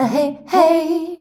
AHEHEY  C.wav